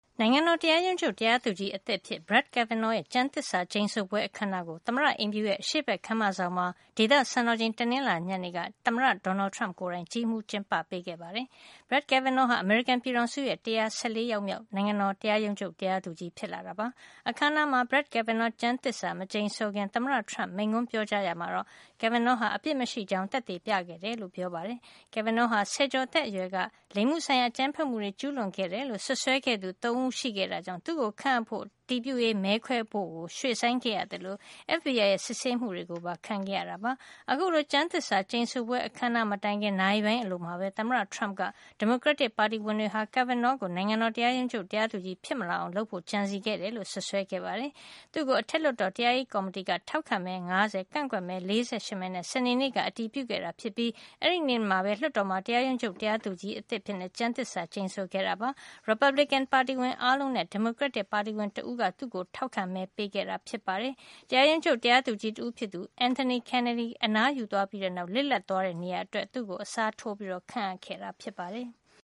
နိုင်ငံတော် တရားရုံးချုပ် တရားသူကြီးအသစ်အဖြစ် Brett Kavanaugh ရဲ့ ကျမ်းသစ္စာ ကျိန်ဆိုပွဲ အခမ်းအနားကို သမ္မတအိမ်ဖြူတော်ရဲ့ အရှေ့ဘက် ခန်းမဆောင်မှာ ဒေသစံတော်ချိန် တနင်္လာနေ့ ညနေက သမ္မတ Donald Trump ကိုယ်တိုင် ကြီးမှူးကျင်းပခဲ့ပါတယ်။